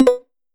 player-ready.wav